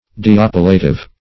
deoppilative - definition of deoppilative - synonyms, pronunciation, spelling from Free Dictionary
Search Result for " deoppilative" : The Collaborative International Dictionary of English v.0.48: Deoppilative \De*op"pi*la*tive\, a. & n. (Med.)